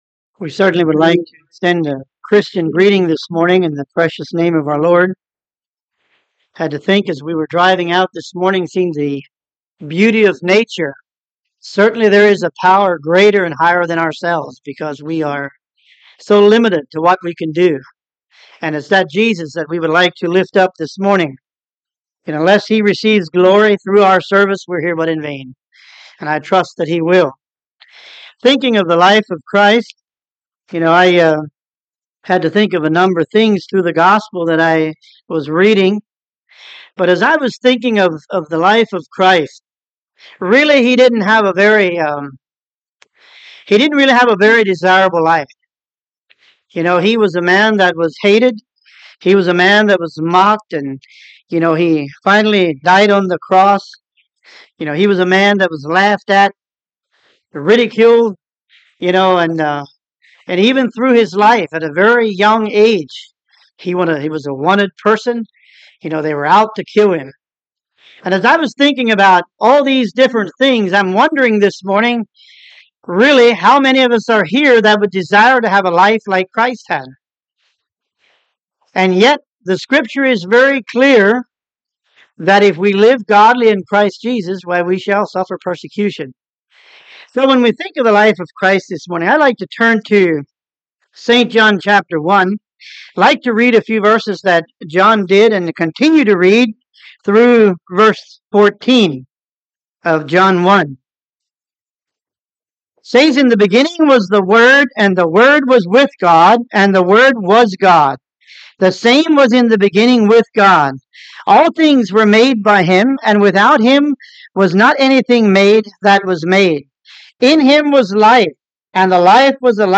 Service Type: Sunday Morning Topics: Life of Christ « Sin Can Never Enter Heaven